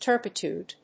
音節tur・pi・tude 発音記号・読み方
/tˈɚːpət(j)ùːd(米国英語), tˈəːpɪtjùːd(英国英語)/